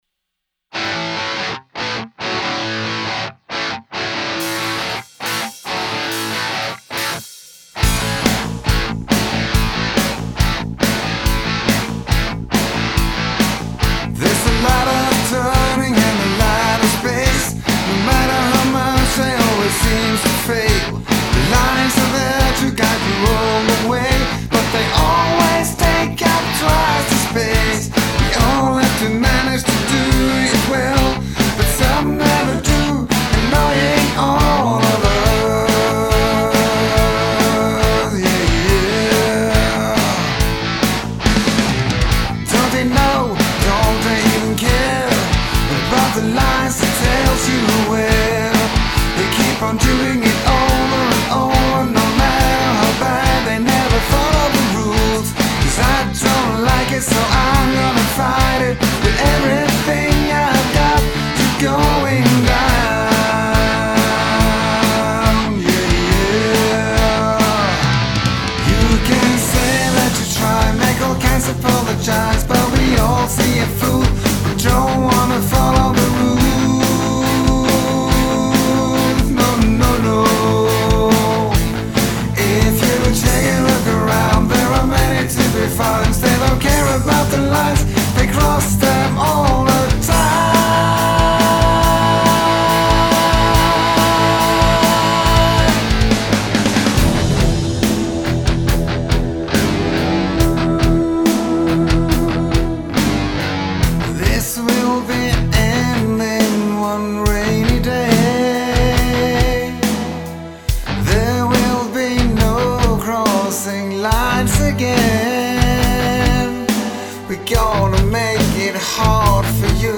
That has thump without rumble.
Ok, yeah there was also some guitar low end at the bridge creating some havoc also.
My latest with more control over low end: